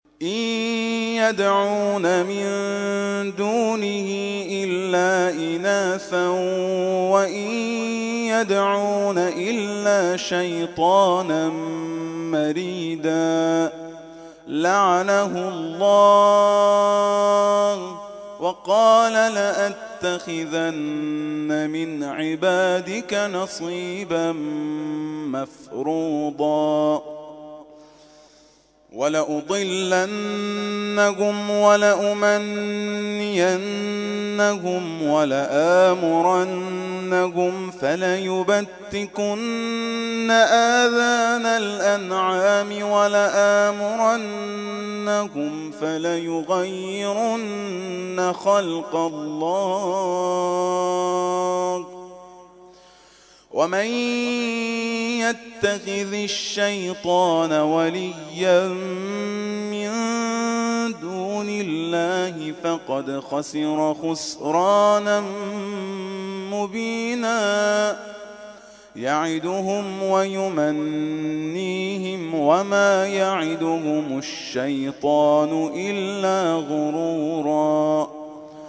گروه جلسات و محافل: محفل انس با قرآن کریم این هفته آستان عبدالعظیم الحسنی(ع) با تلاوت دو قاری ممتاز کشورمان و ارائه محفوظات حافظ کل قرآن برگزار شد.
این محفل انس با قرآن هر هفته جمعه‌ها با حضور زائران و مجاوران این آستان مقدس برگزار می‌شود.